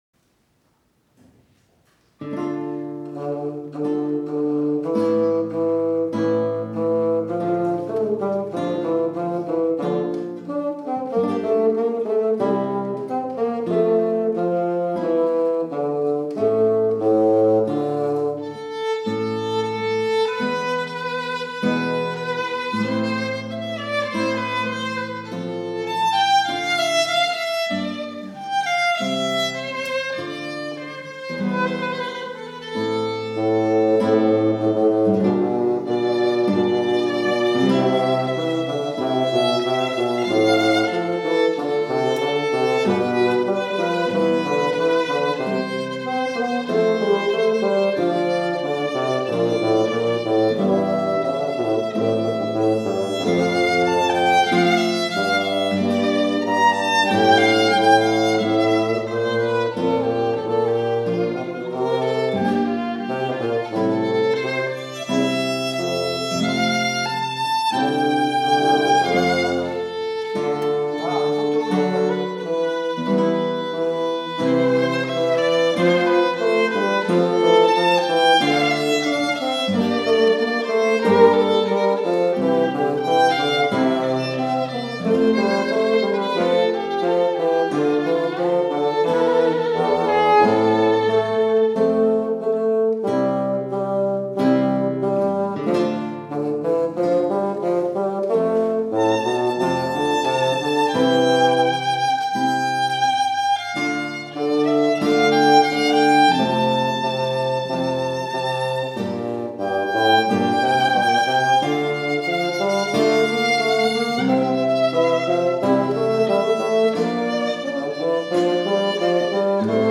Programme des auditions
Trio violon: guitare : basson